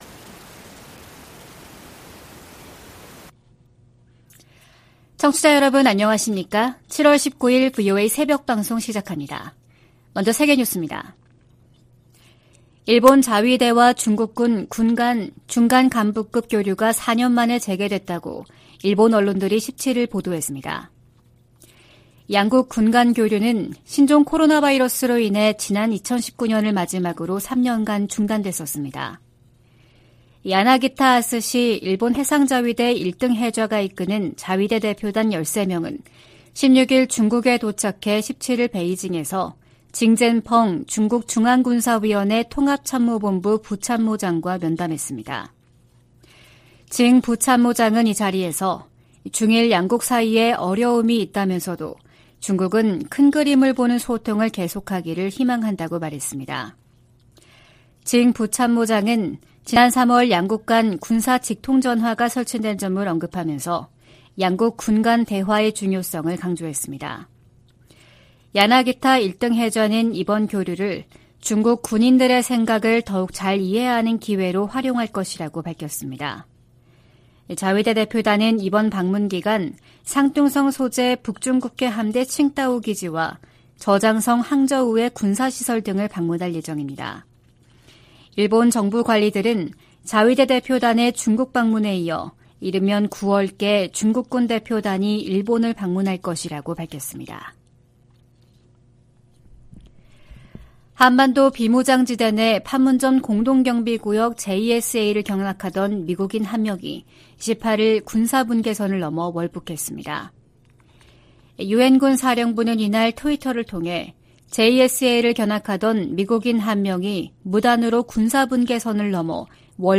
VOA 한국어 '출발 뉴스 쇼', 2023년 7월 19일 방송입니다. 미국과 한국은 18일 서울에서 핵협의그룹(NCG) 첫 회의를 갖고 북한이 핵 공격을 할 경우 북한 정권은 종말을 맞을 것이라며, 확장억제 강화의지를 재확인했습니다. 미 국무부는 북한의 도발에 대한 유엔 안보리의 단합된 대응을 촉구했습니다. 아세안지역안보포럼(ARF)이 의장성명을 내고, 급증하는 북한의 탄도미사일 발사가 역내 평화를 위협한다고 비판했습니다.